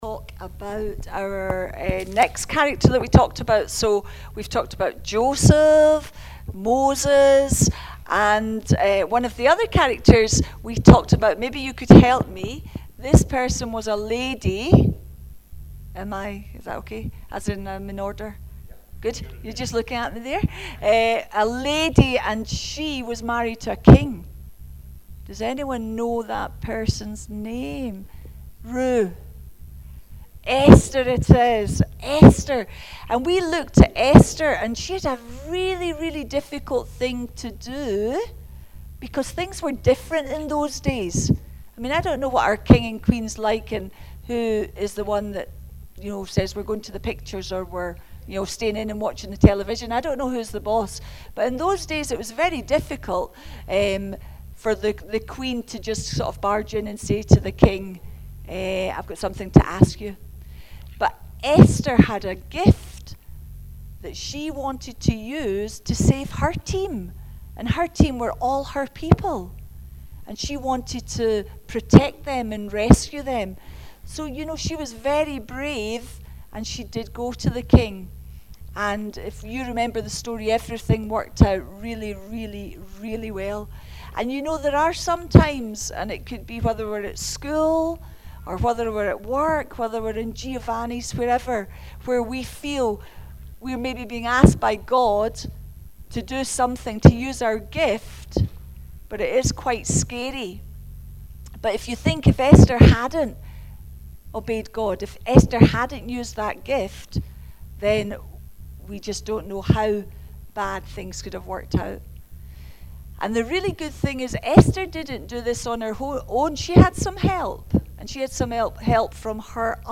Teambuilders Holiday Club Family Service